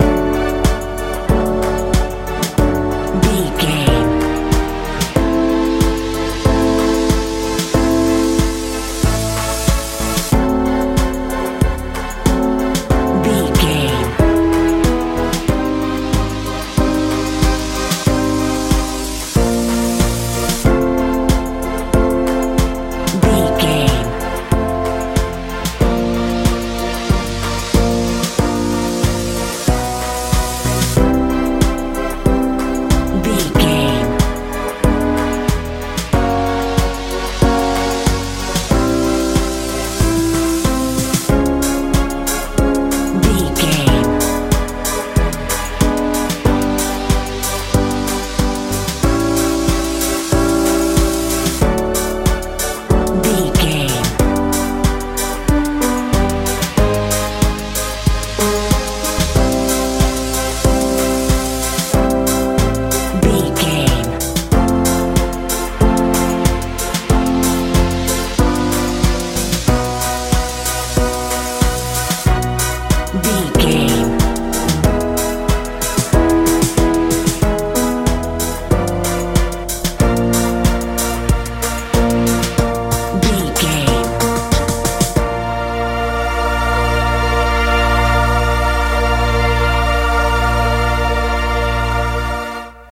modern dance
Ionian/Major
D
confused
anxious
piano
synthesiser
bass guitar
drums
80s
suspense
tension